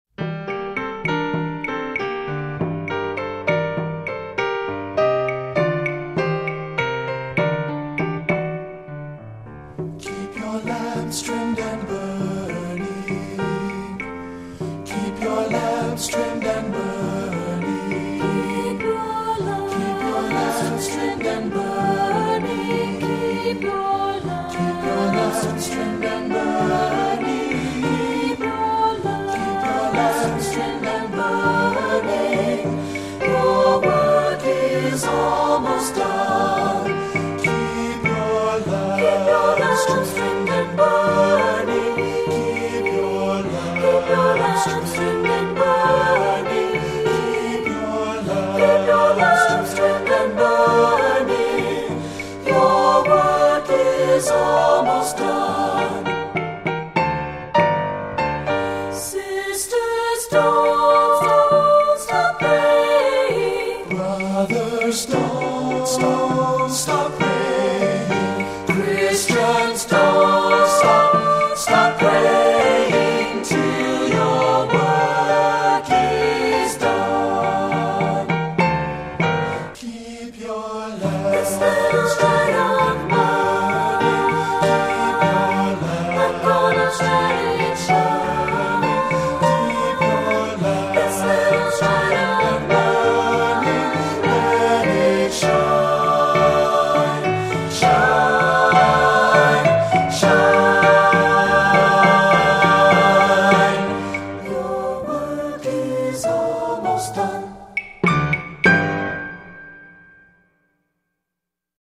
Composer: African American Spiritual
Voicing: SAT